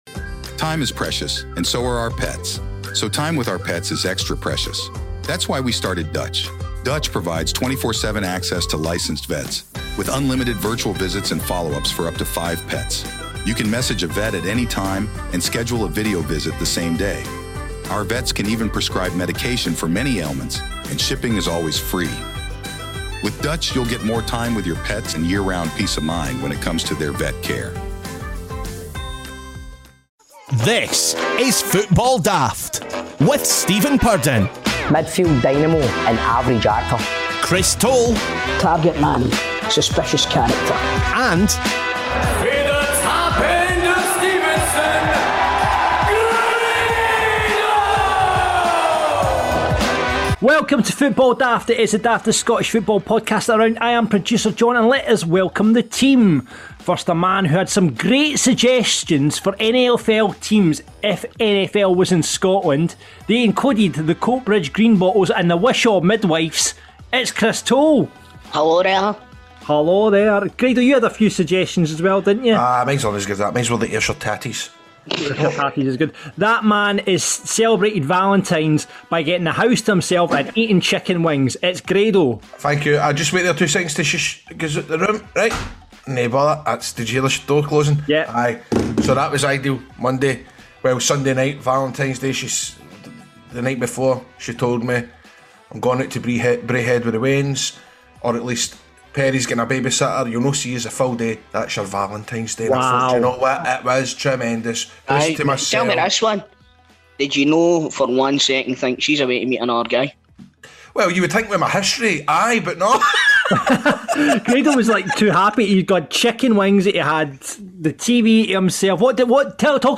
We are also joined by a special guest Aberdeen supporter to find out where it has all gone wrong this season for the Dons.